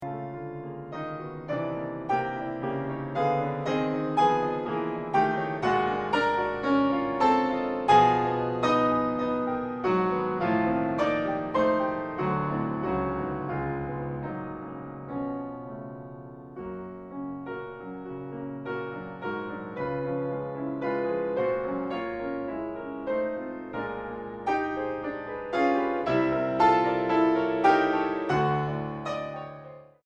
Piano Bösendorfer 290 Imperial.